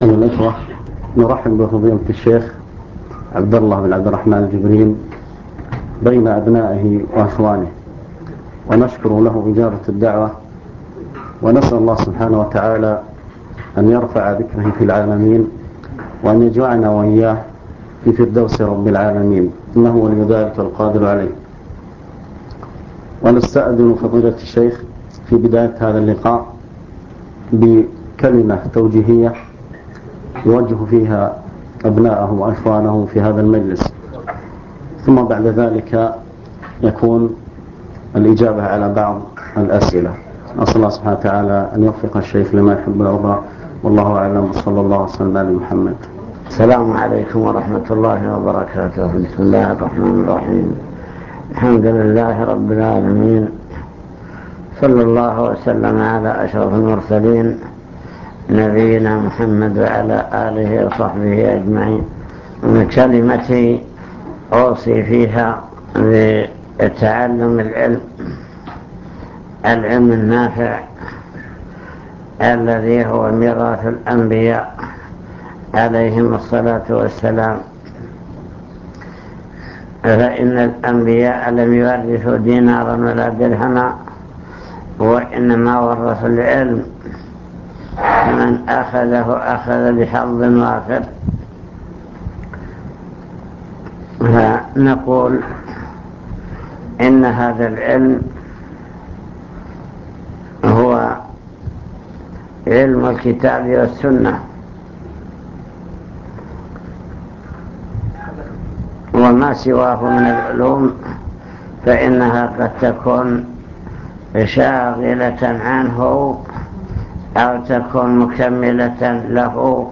المكتبة الصوتية  تسجيلات - لقاءات  لقاء في عنك